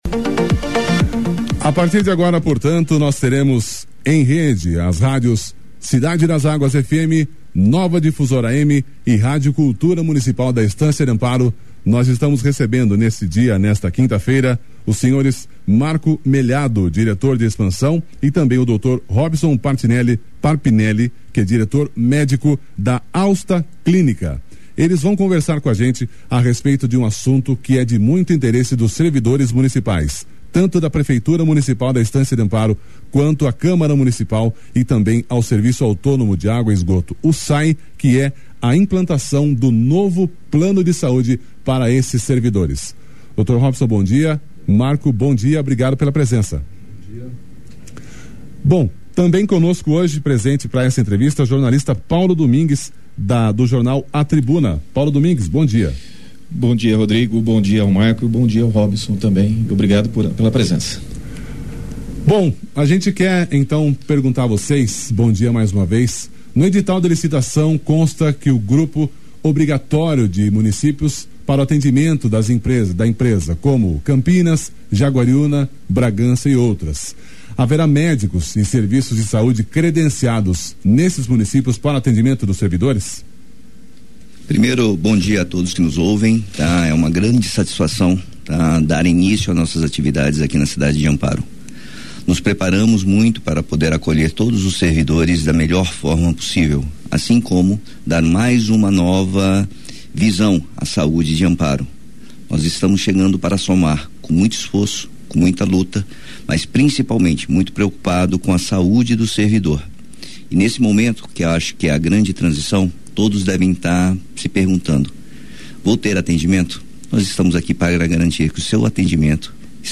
Ouça a entrevista com representantes da Austa Clínicas sobre o plano de saúde dos servidores de Amparo - Cidade das águas FM